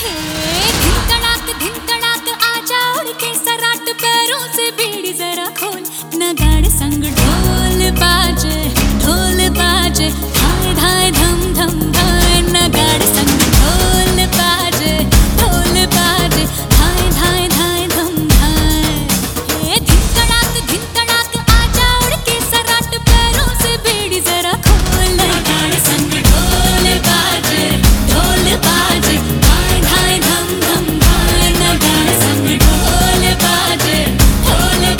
# Bollywood